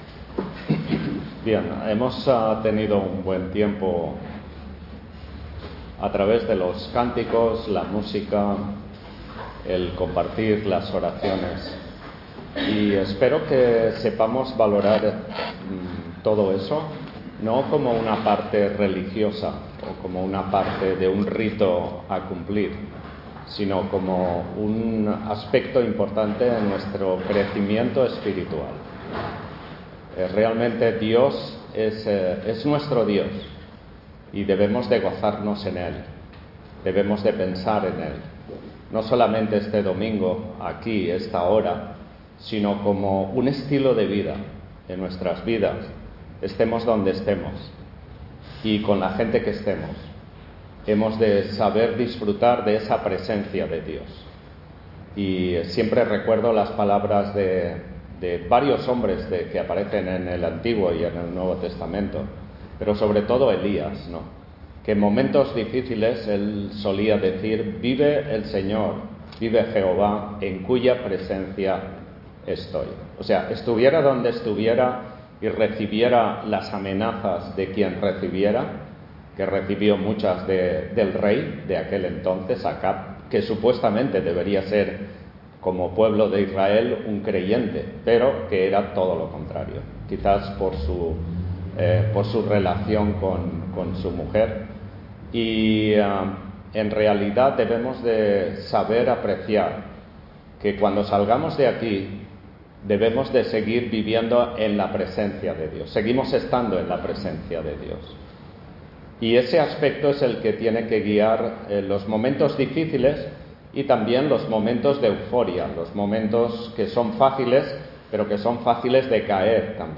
Service Type: Culto Dominical